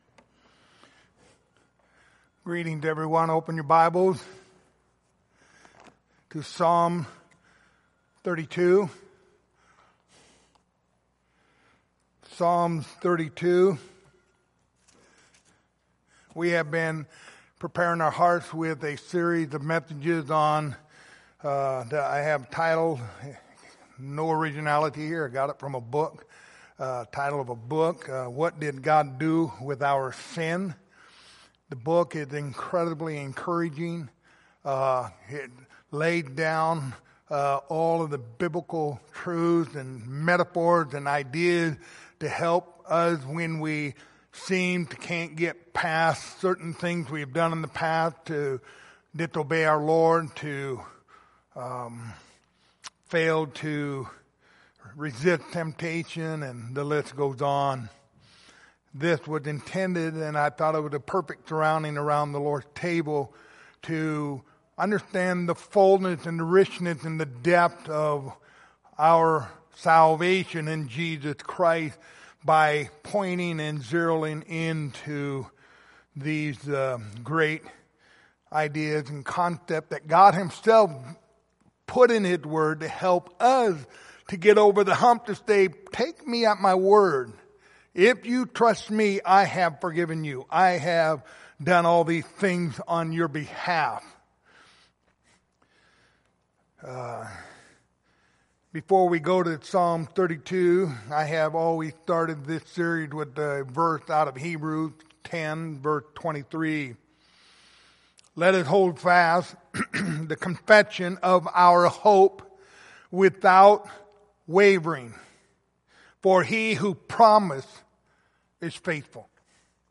Lord's Supper Passage: Psalms 32:1-11 Service Type: Lord's Supper Topics